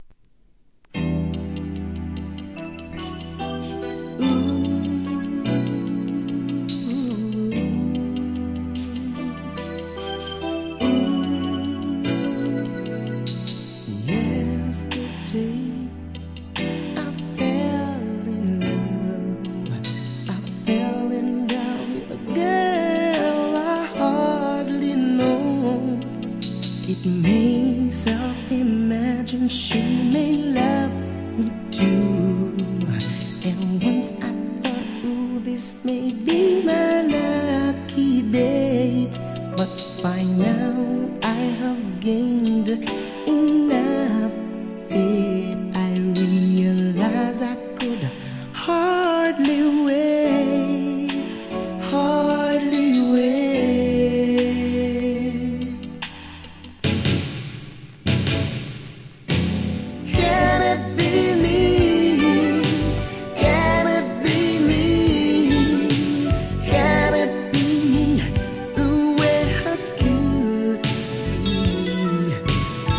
REGGAE
1990年、LOVEソングからシリアス・チュ〜ンまで優しいヴォーカルで歌い上げます!!